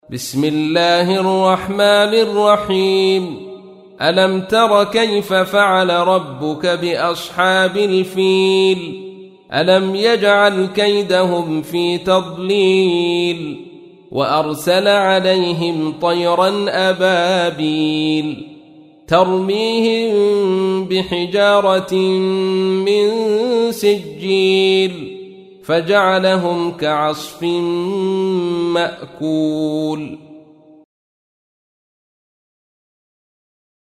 تحميل : 105. سورة الفيل / القارئ عبد الرشيد صوفي / القرآن الكريم / موقع يا حسين